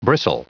Prononciation du mot bristle en anglais (fichier audio)
Prononciation du mot : bristle